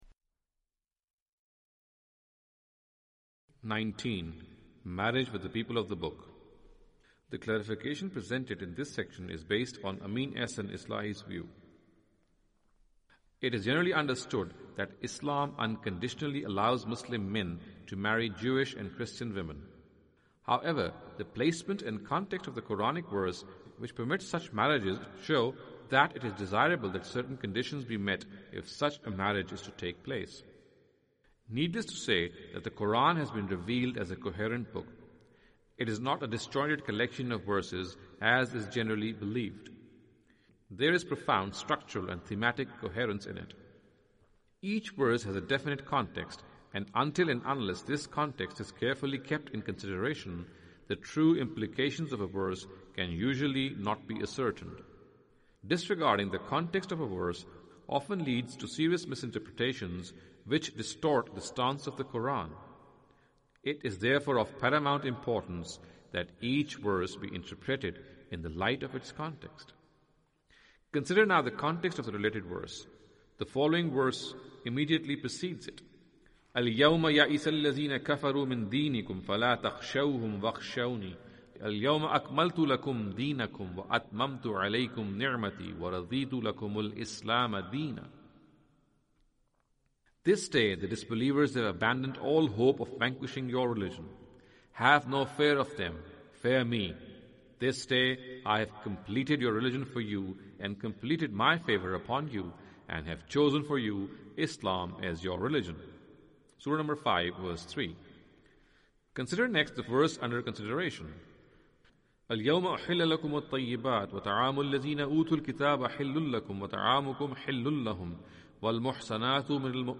Audio book of English translation of Javed Ahmad Ghamidi's book "Islam and Women".